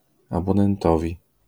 IPA: [abɔnɛnˈtɔvʲi]